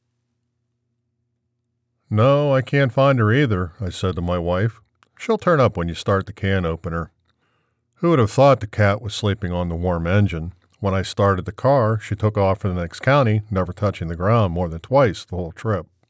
As requested, no effects or alterations were done to this recording…
It sounds good, for most purposes, excellent.
I can hear a motor/fan on “can opener”.